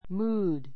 mood A2 múːd ム ー ド 名詞 気分, 機嫌 きげん He is in a bad [good] mood.